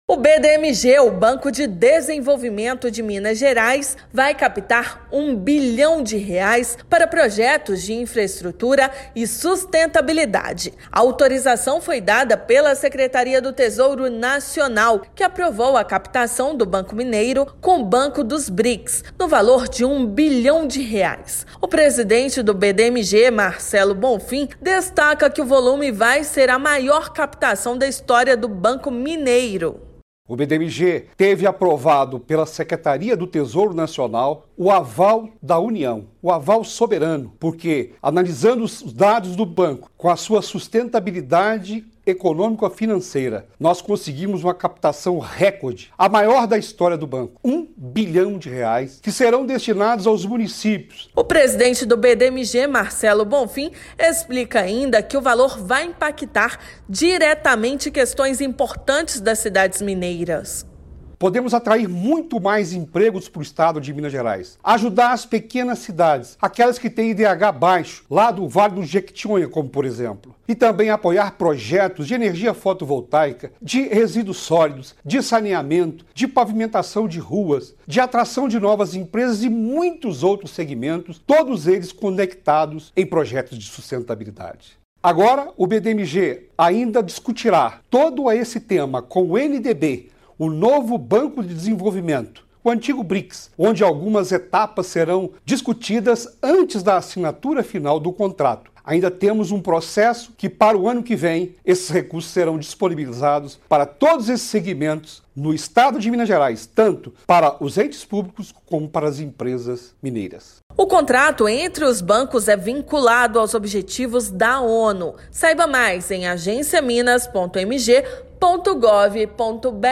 Tesouro Nacional autoriza esta que será a maior operação da história do banco mineiro. Contrato é vinculado aos Objetivos da ONU. Ouça matéria de rádio.